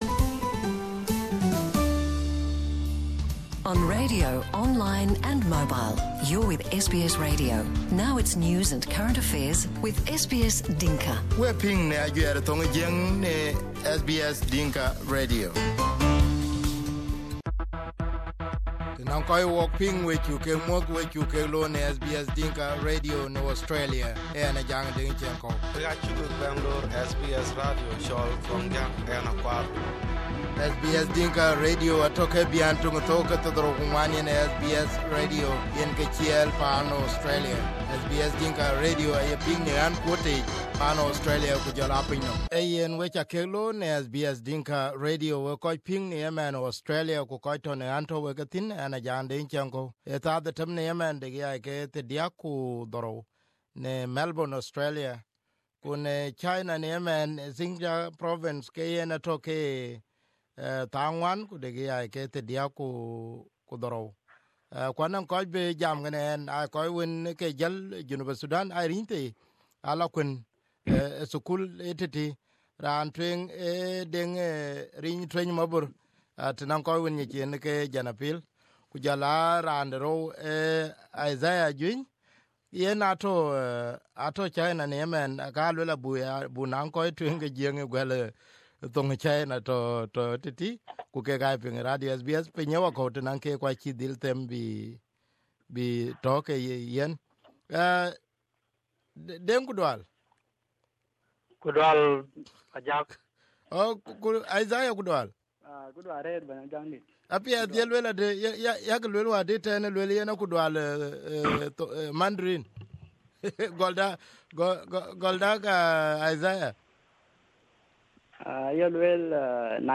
Here their interview on SBS Dinka Radio.